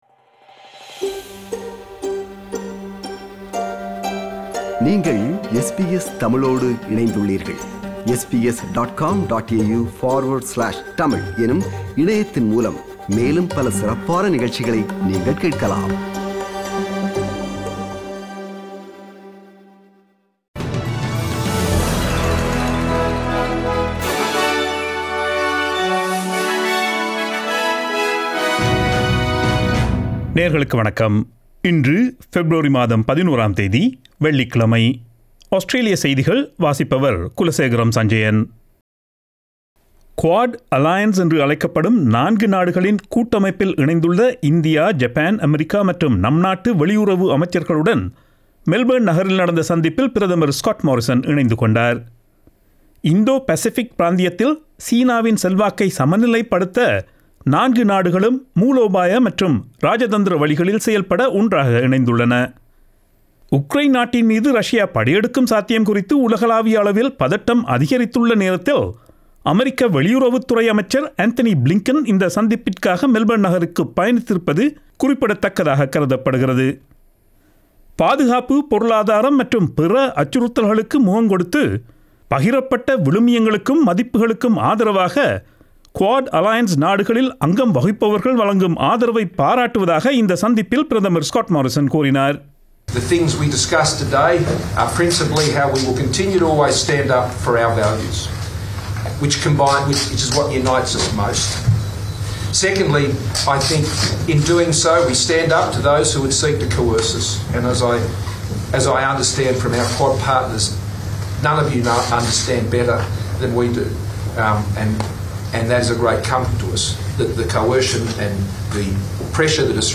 Australian news bulletin for Friday 11 February 2022.